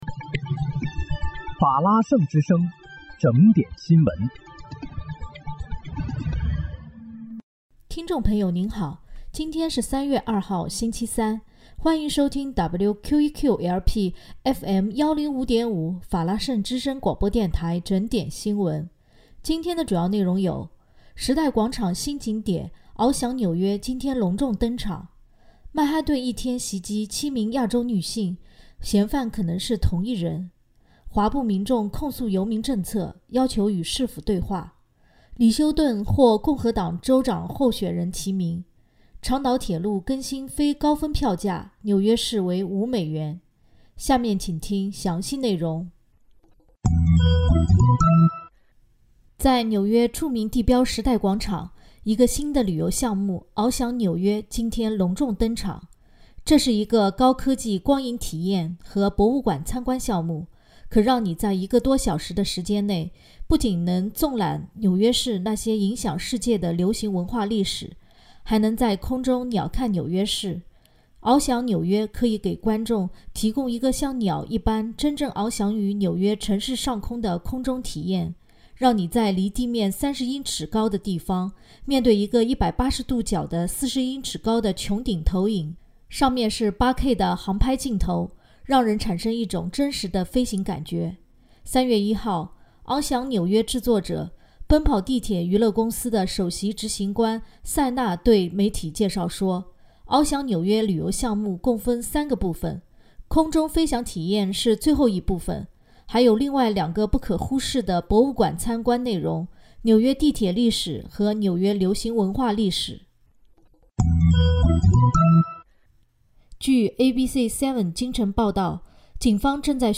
3月2日（星期三）纽约整点新闻